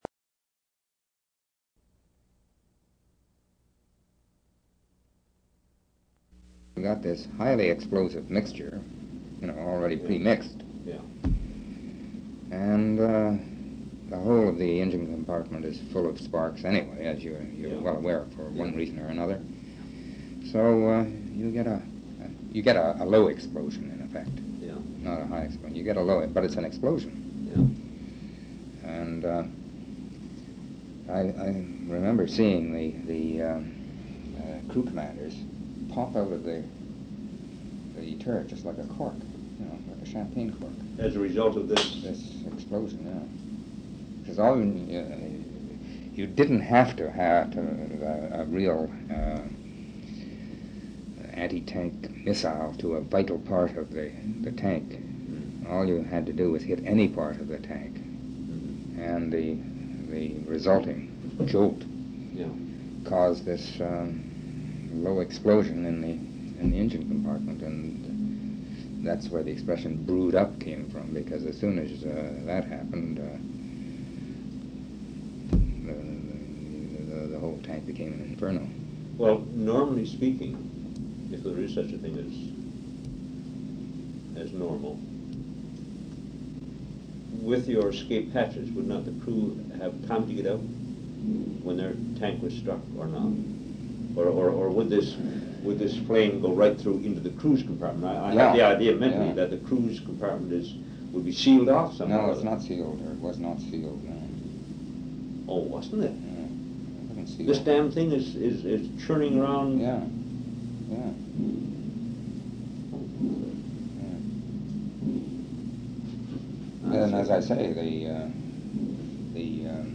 Interview took place on June 16, 1981.